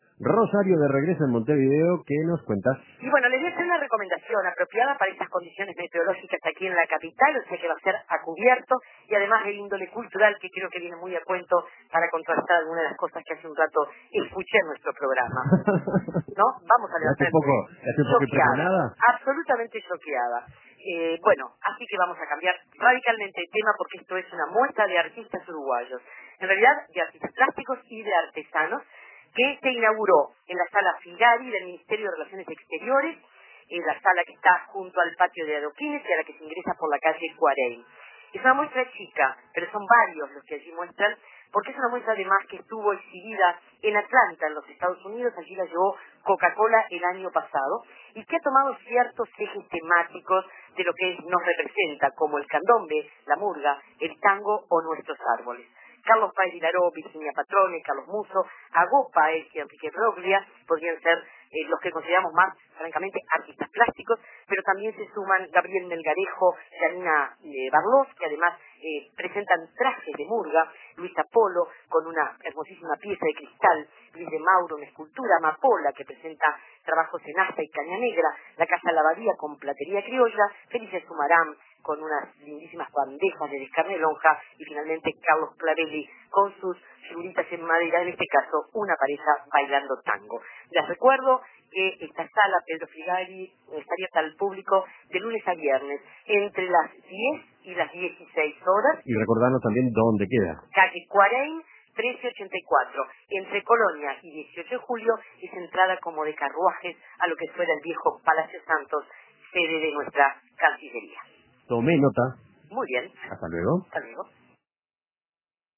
Plásticos y artesanos uruguayos exponen en el Ministerio de Relaciones Exteriores. Móvil